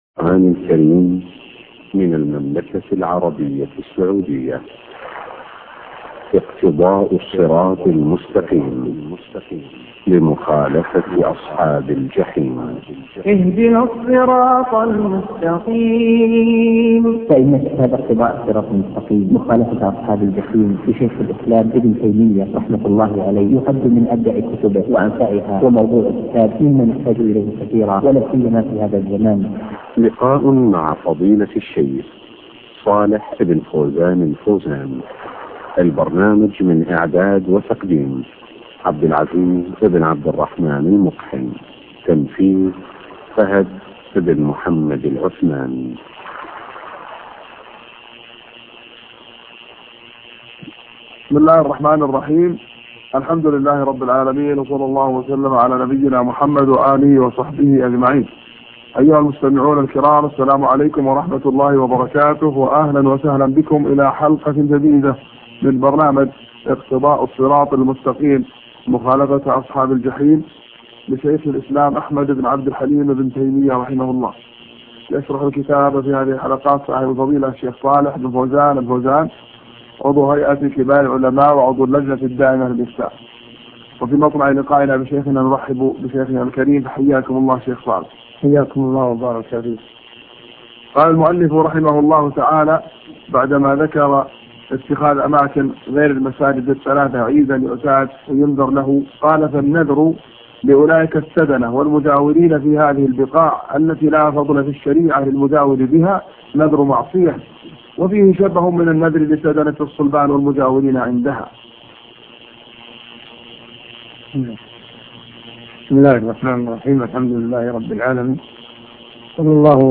اقتضاء الصراط المستقيم شرح الشيخ صالح بن فوزان الفوزان الدرس 112